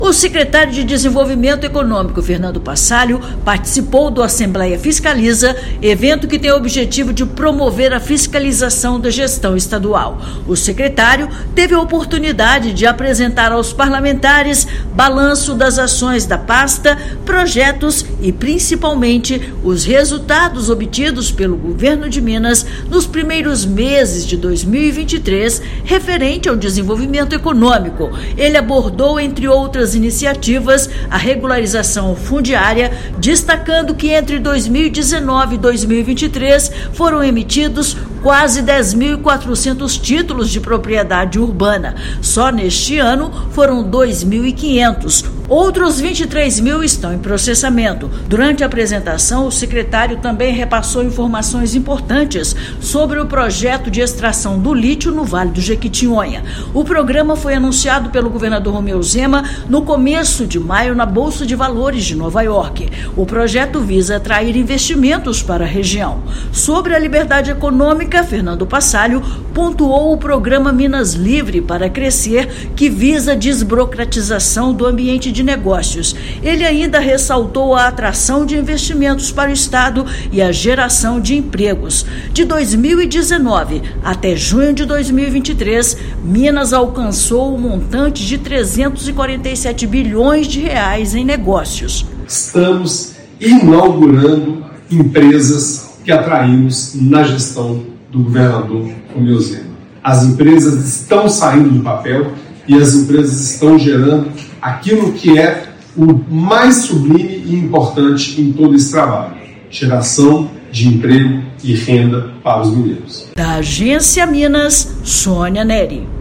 Secretário Fernando Passalio elencou as ações e projetos da Sede, que vêm alcançando muitos resultados positivos para o estado. Ouça a matéria de rádio.